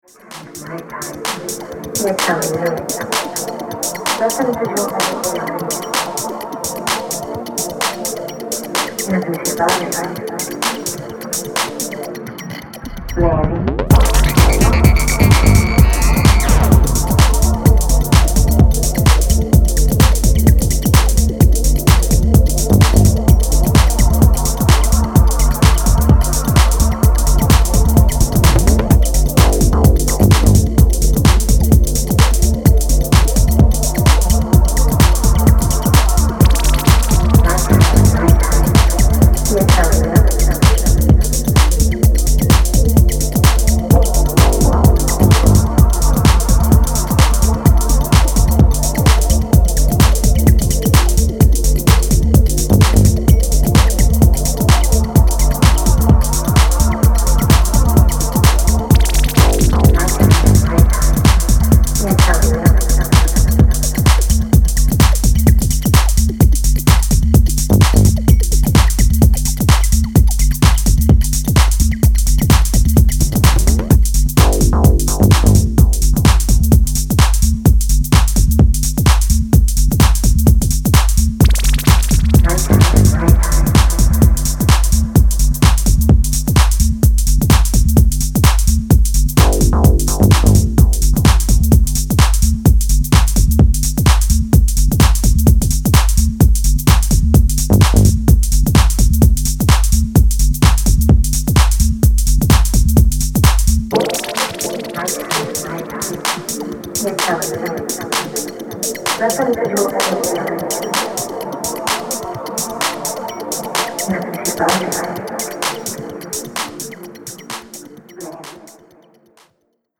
house, techno, and electro
House Acid Breaks Trance